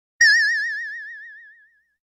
ethyethiiywaithy 2 Meme Sound Effect
Category: Reactions Soundboard